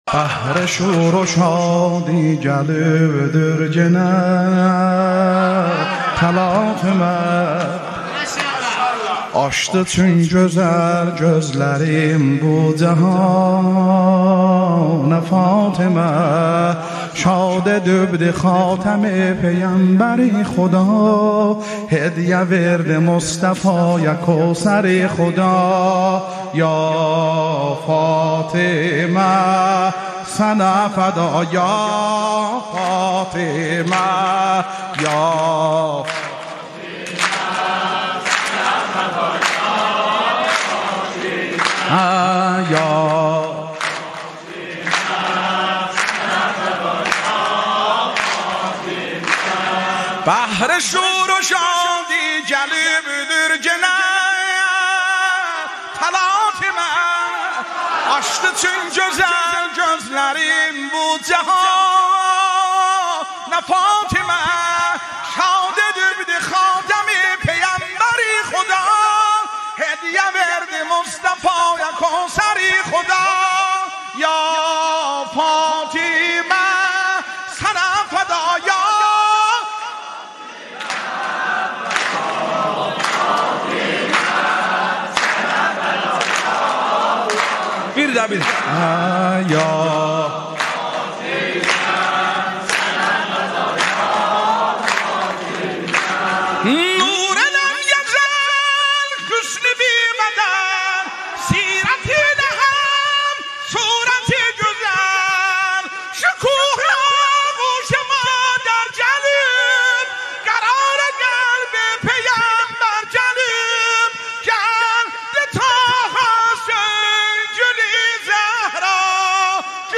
مولودی آذری